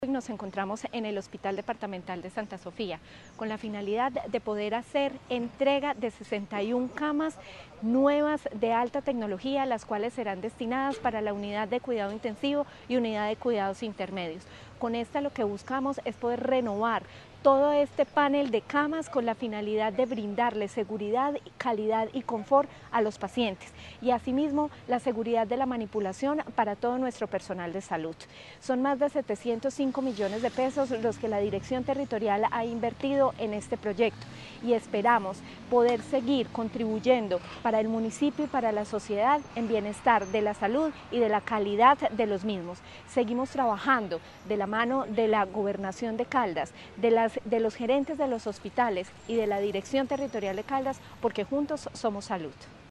Natalia Castaño Díaz, directora de la DTSC.